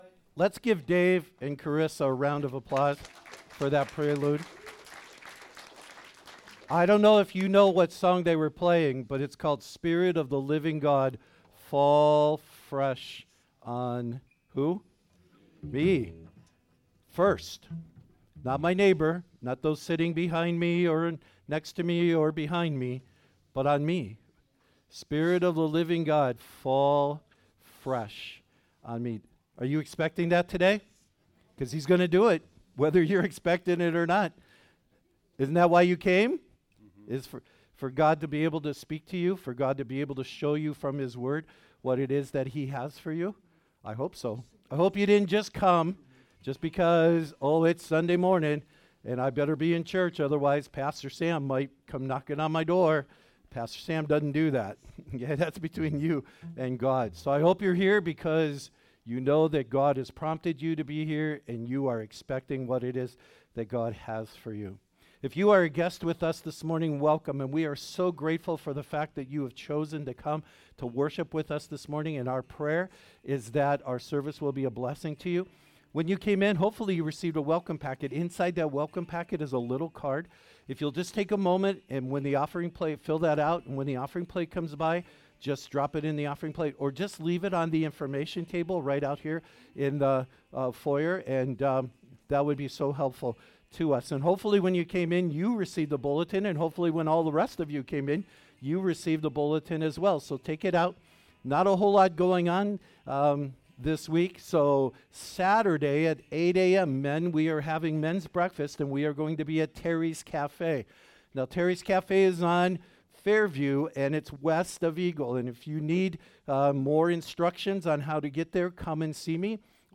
2023 Sermons http